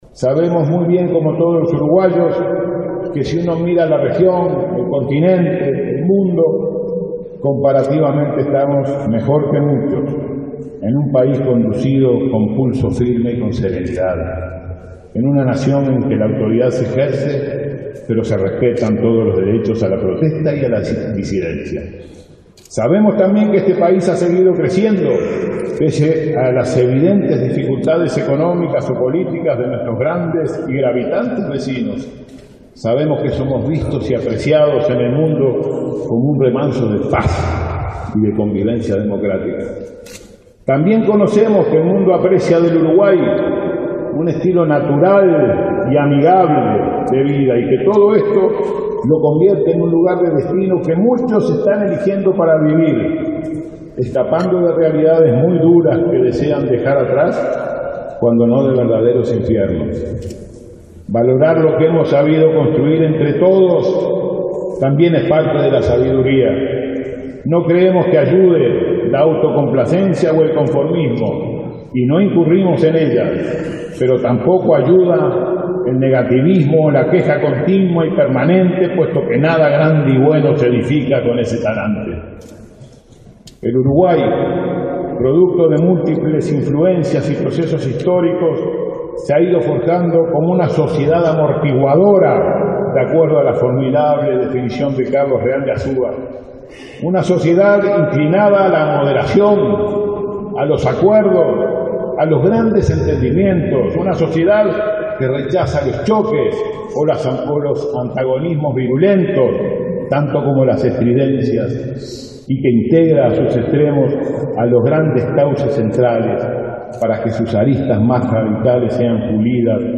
El canciller Nin Novoa fue el orador del Ejecutivo en el acto por el 187.° aniversario de la Jura de la Constitución. Repasó los logros del Gobierno y los esfuerzos en políticas sociales, educativas y de seguridad, que tienen como centro a los más necesitados.